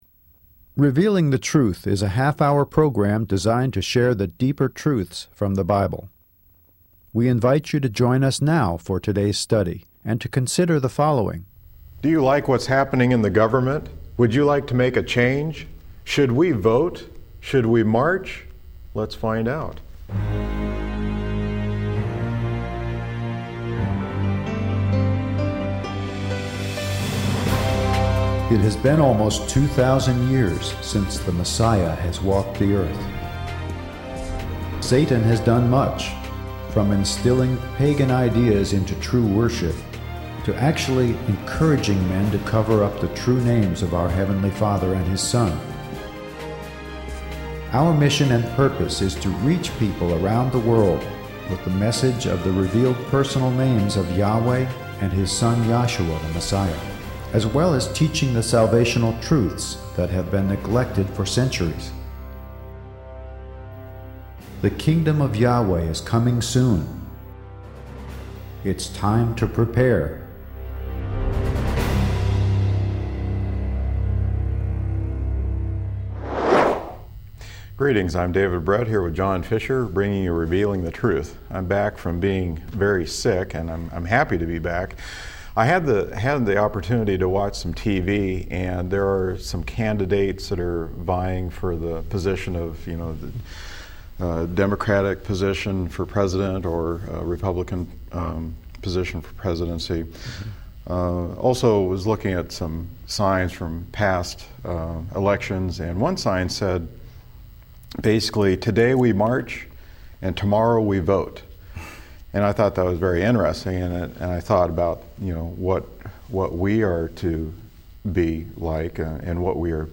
LISTEN NOW TO "RADIO" BROADCASTS OF REVEALING THE TRUTH!